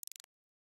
Collapse.wav